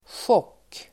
Uttal: [sjåk:]
chock.mp3